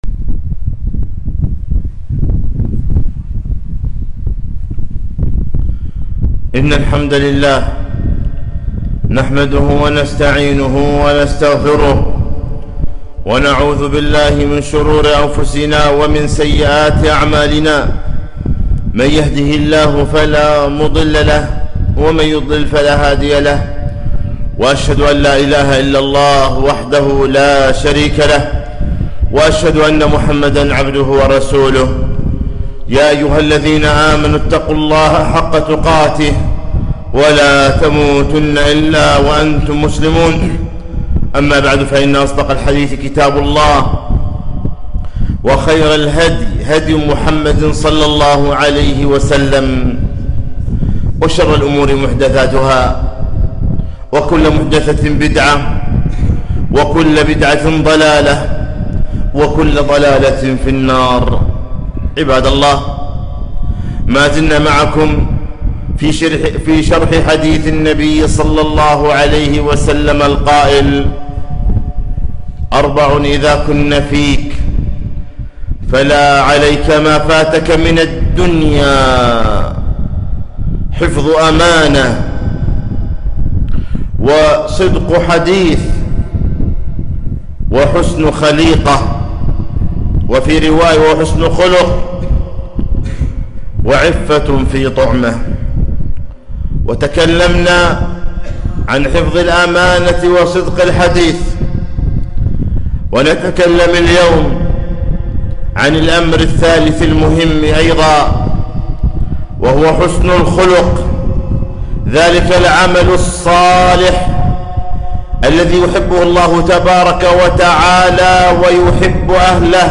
خطبة - جاهد نفسك على الخلق الحسن